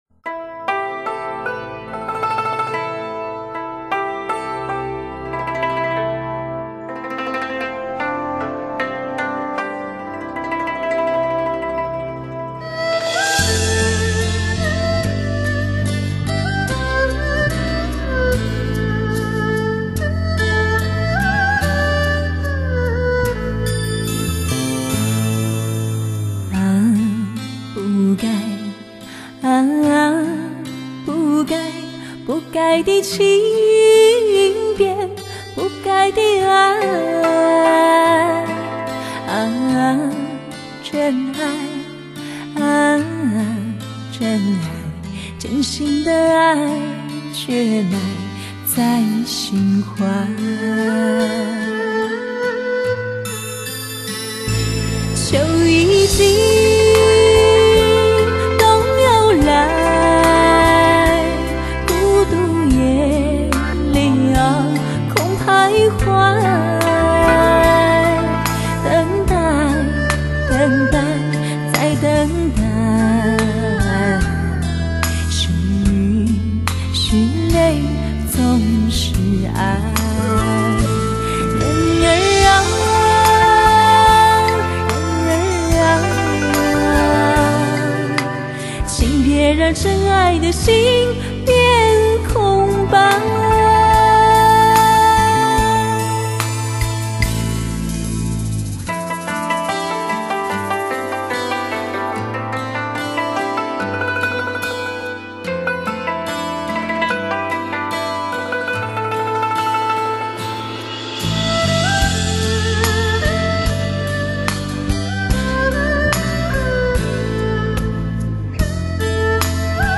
醉人的声线 完美的配器
吉它
二胡
古筝
小提琴
一张不可多得的人声示范发烧碟，13首贵精不贵多的歌曲，有民歌、有流行，有粗犷的大漠之声，有甜美可人的菲菲之音。